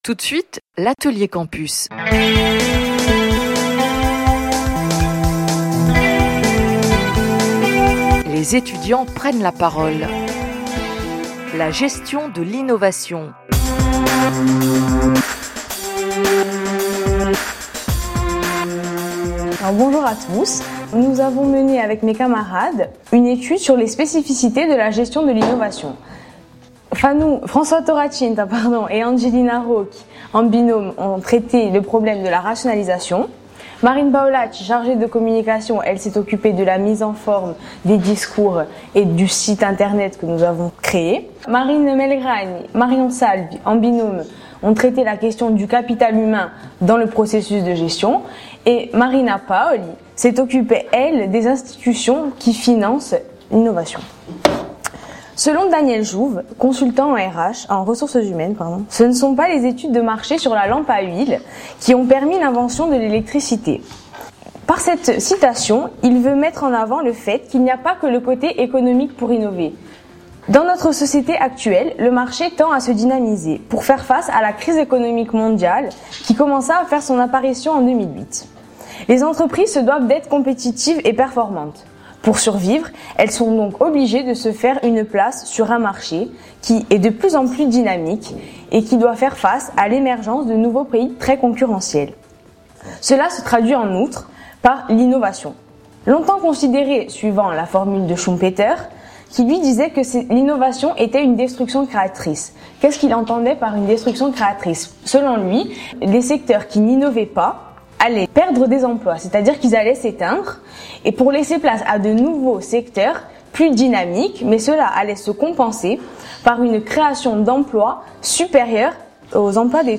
debat_gerer.mp3